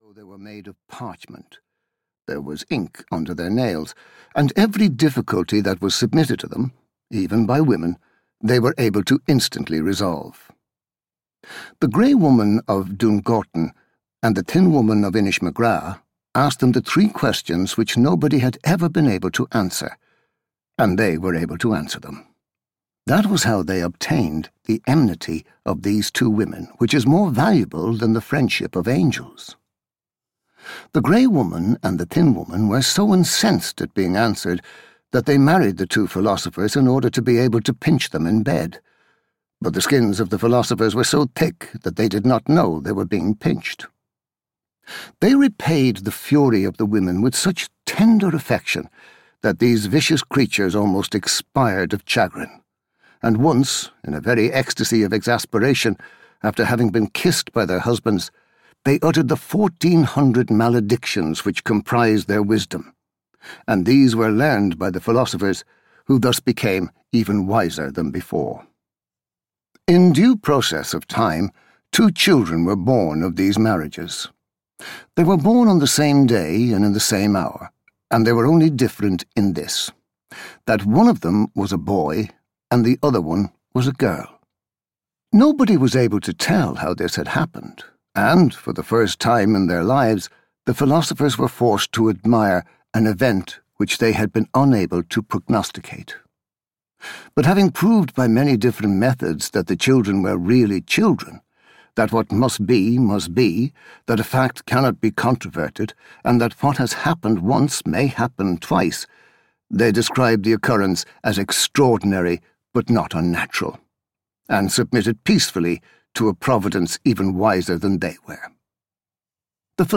Audio knihaThe Crock of Gold (EN)
Ukázka z knihy